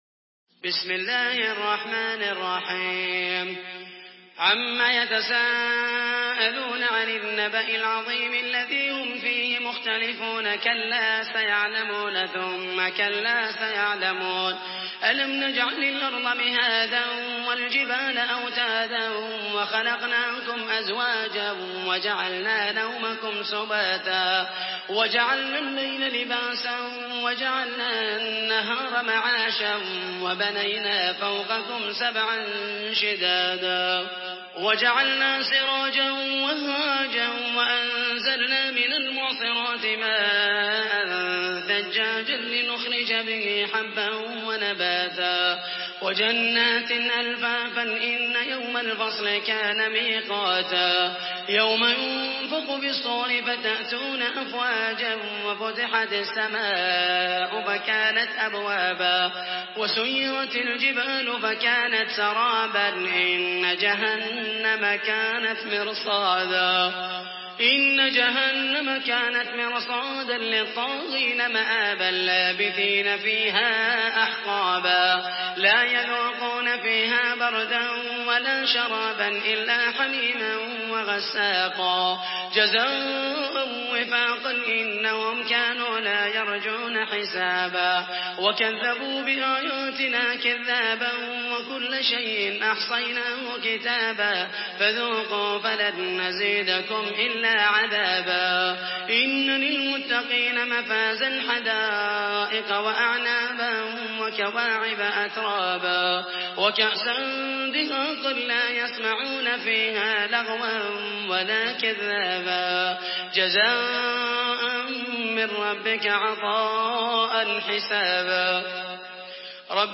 Surah আন-নাবা MP3 in the Voice of Muhammed al Mohaisany in Hafs Narration
Murattal Hafs An Asim